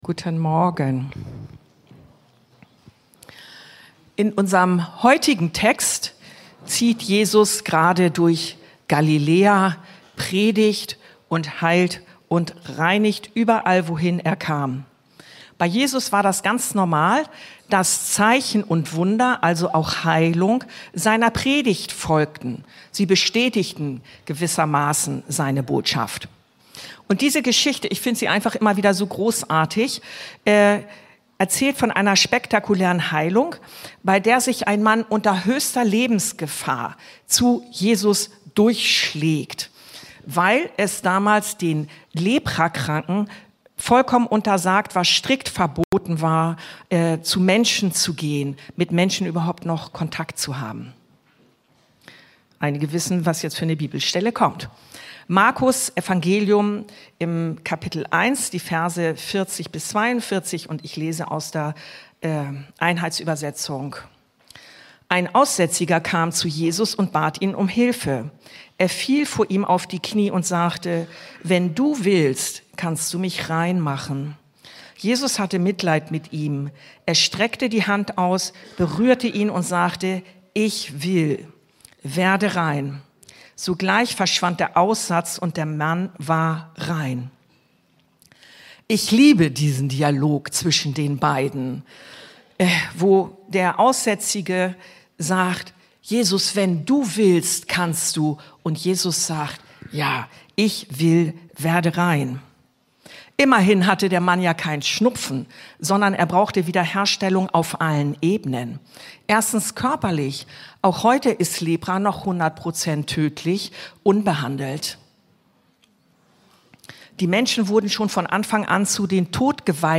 ICH WILL! (Mk 1,40-42) ~ Anskar-Kirche Hamburg- Predigten Podcast